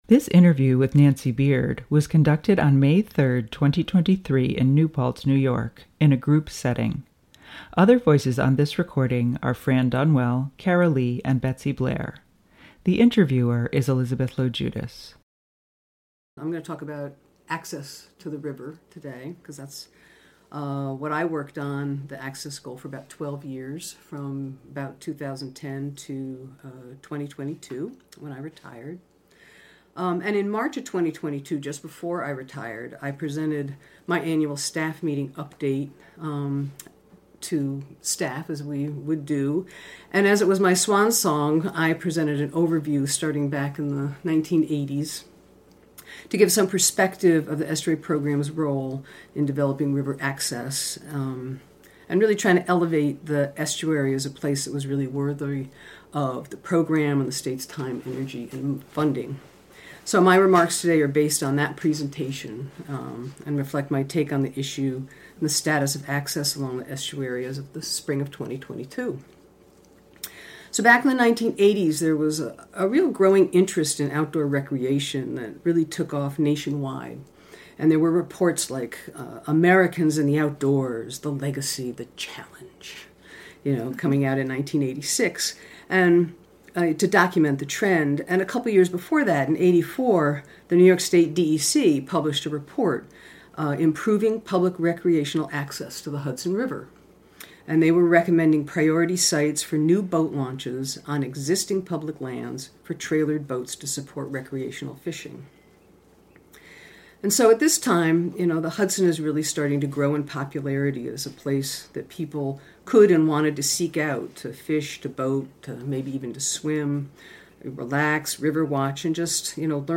Women of the River Oral Histories